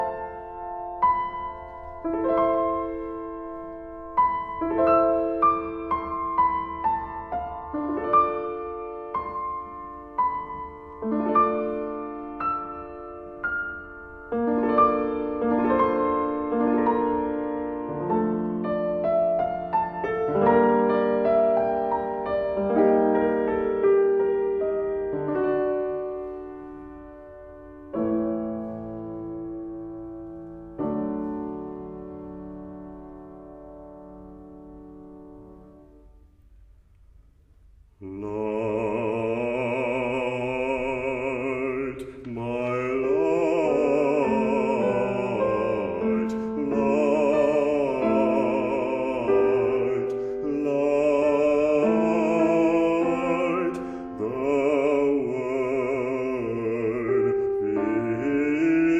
Für Bass und Klavier
Neue Musik
Vokalmusik
Duo
Bass (1), Klavier (1)